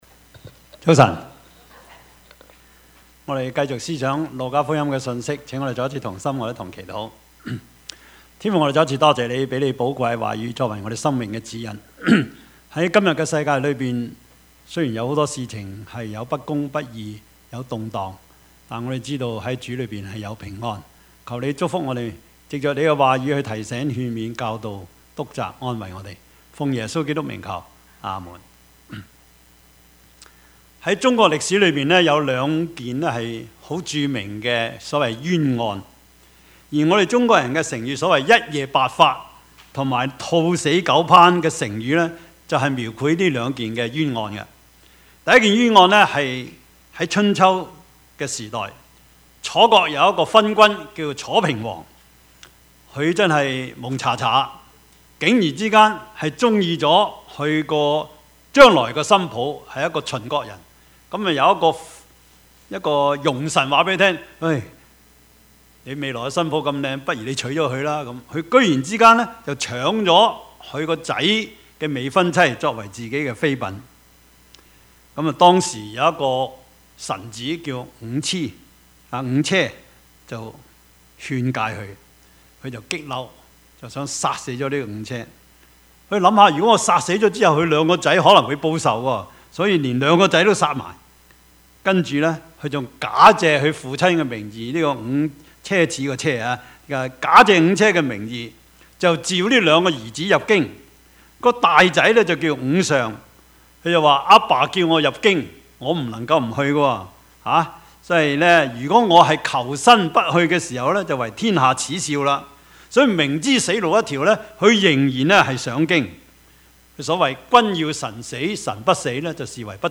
Service Type: 主日崇拜
Topics: 主日證道 « 是人是獸 基督教倫理學(二) »